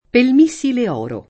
missile [m&SSile] agg. e s. m. — es. con acc. scr.: pel mìssile oro [